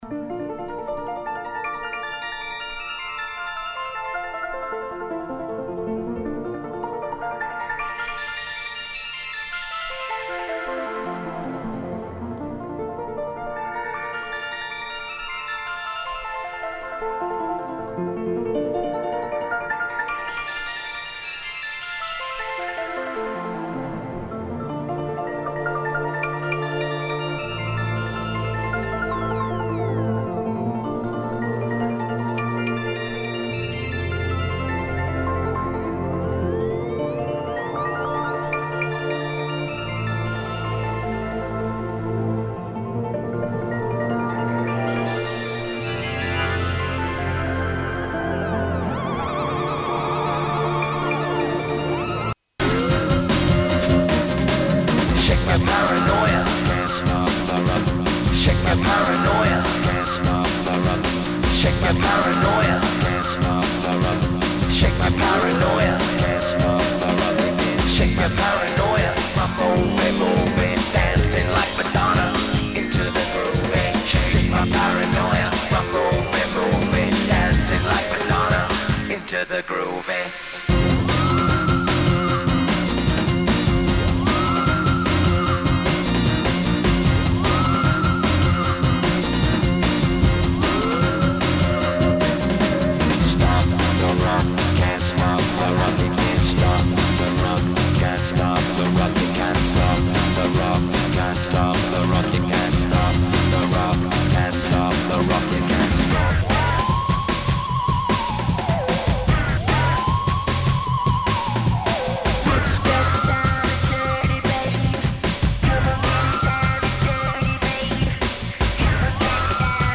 ⌂ > Vinyly > Jungle-Drum&Bass >